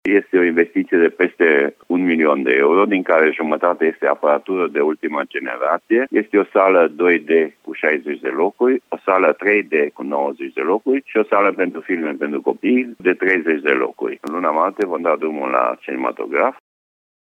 Cinematograful din Lugoj se redeschide în luna martie. Primarul Francisc Boldea spune că în această perioadă se semnează contractele cu casele de distribuţie a filmelor iar săptămâna viitoare va fi angajat personalul cinematografului.
Francisc-Boldea-cinematograf.mp3